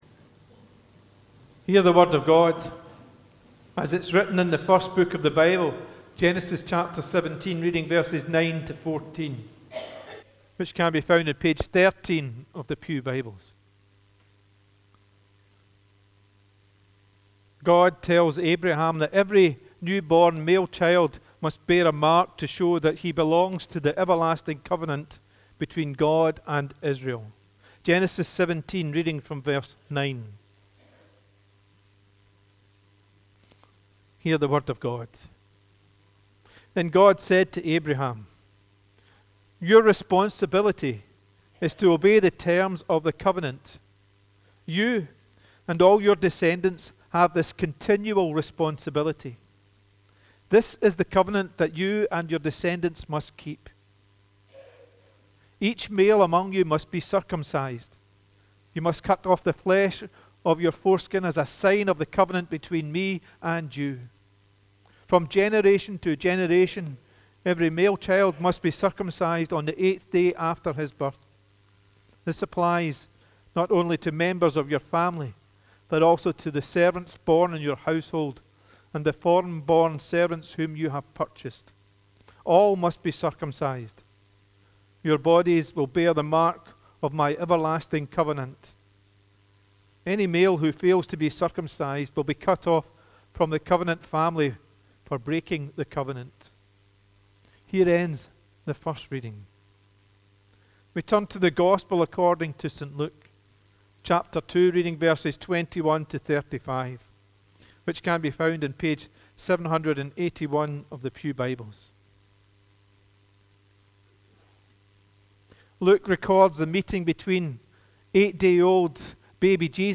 The readings prior to the sermon are Genesis 17: 9-14 and Luke 2: 21-35